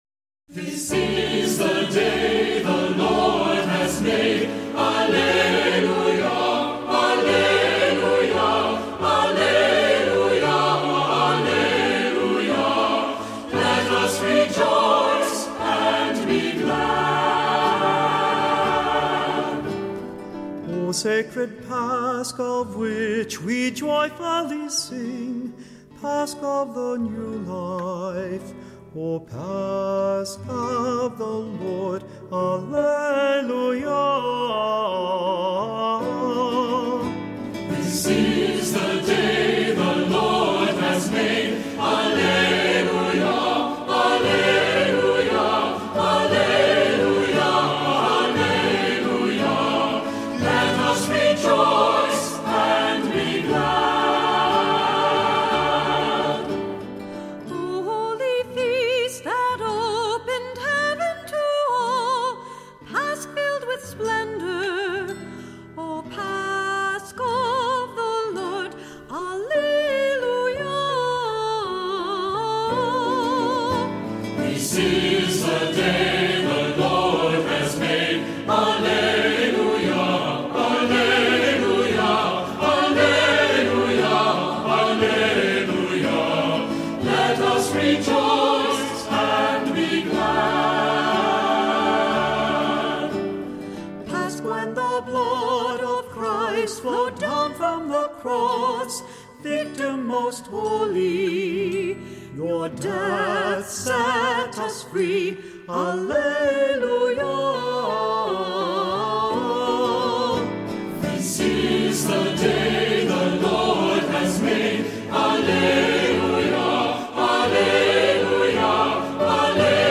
Voicing: Assembly,Cantor